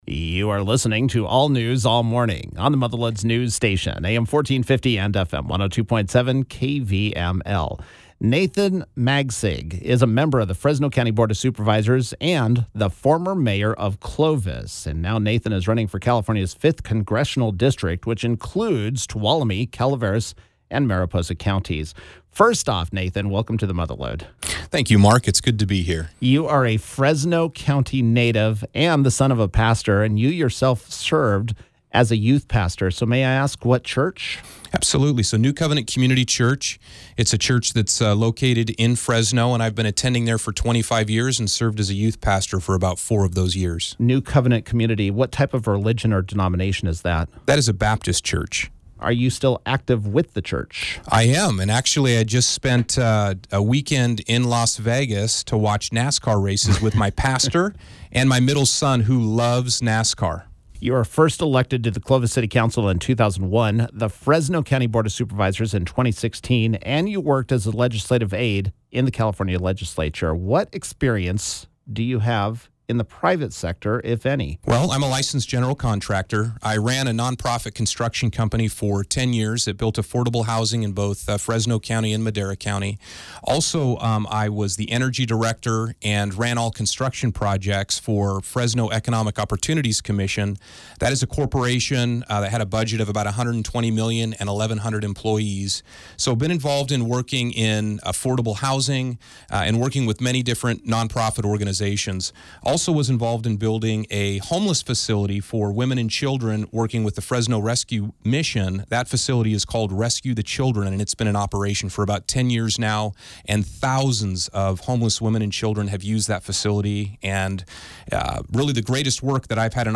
Candidate Interview: Nathan Magsig Is Running For California’s 5th Congressional District
Magsig stopped by the studio and was Friday’s KVML “Newsmaker of the Day”.